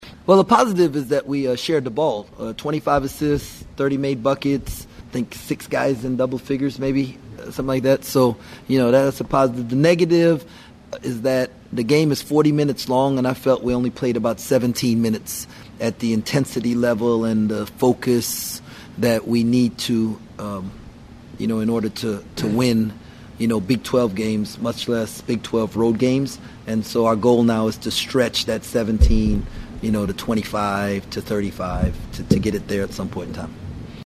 Kansas State Coach Jerome Tang said there were several positives from the win.